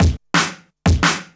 Cops 175bpm.wav